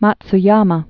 (mäts-yämä)